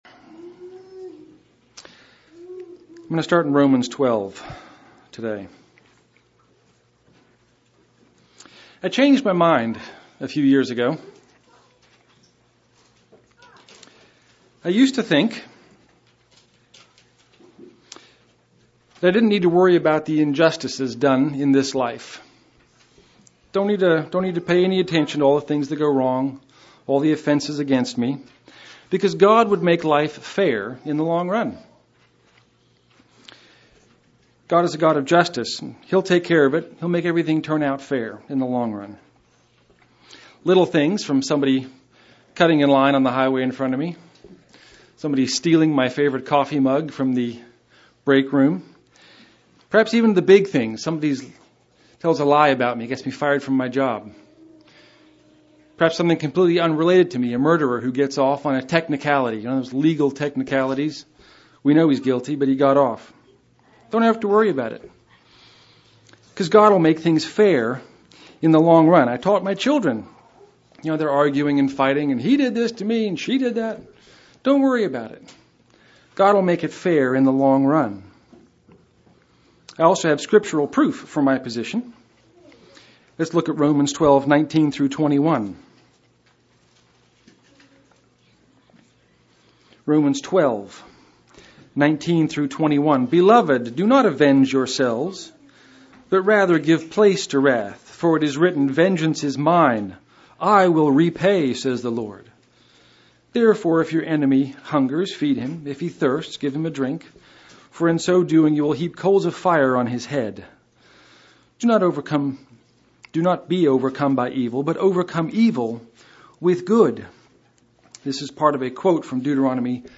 Given in Lawton, OK
UCG Sermon vengeance justice. judgment of God justice fairness Studying the bible?